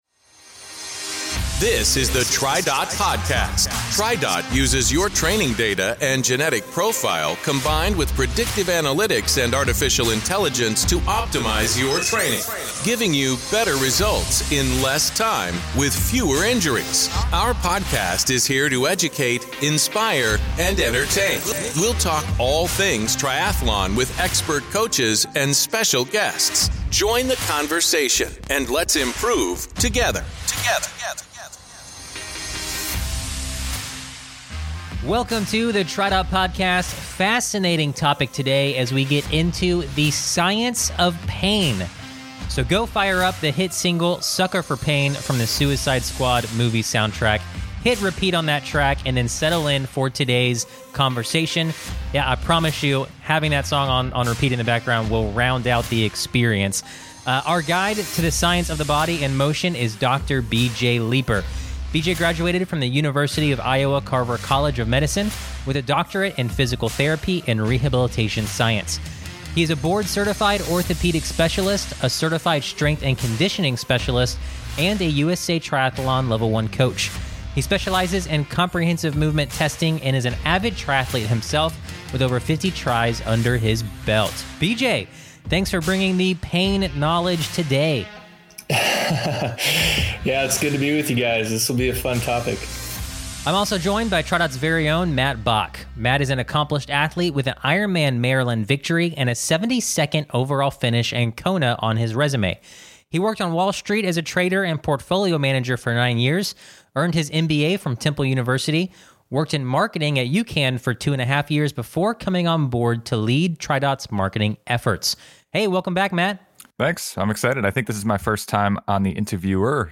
physical therapist and TriDot coach, answers these questions, and more!